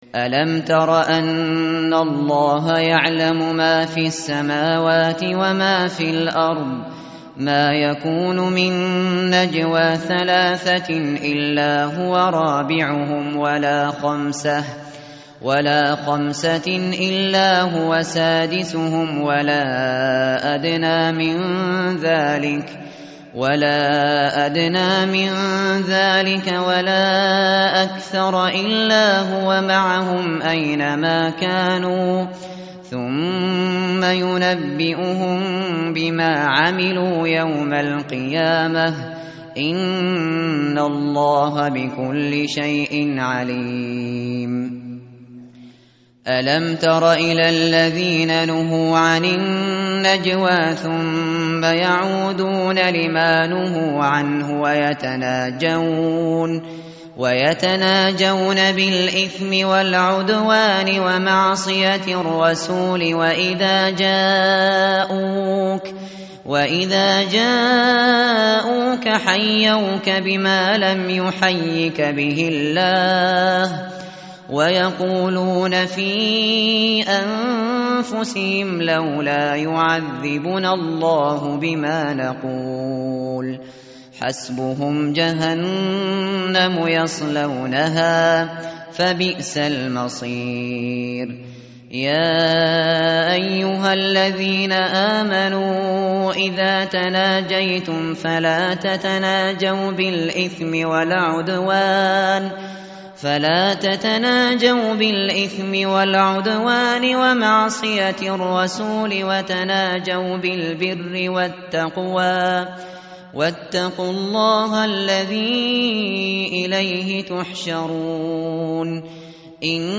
متن قرآن همراه باتلاوت قرآن و ترجمه
tartil_shateri_page_543.mp3